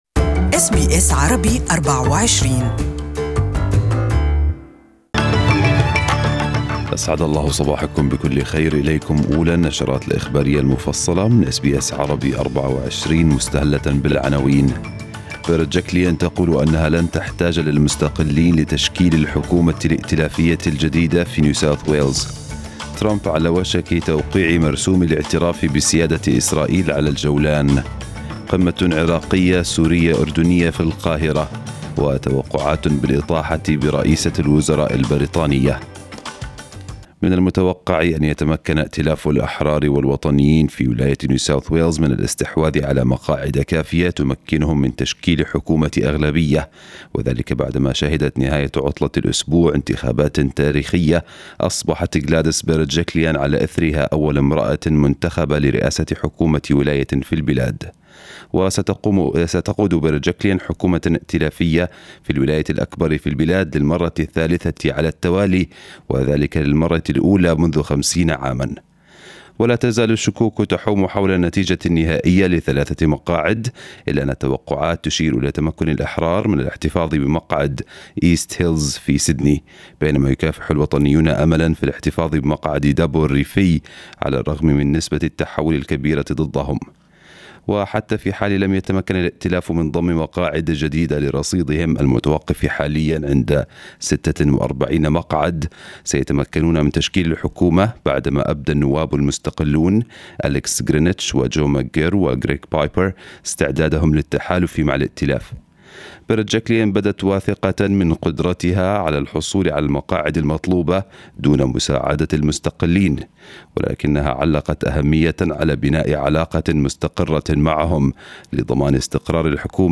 نشرة الأخبار باللغة العربية لهذا الصباح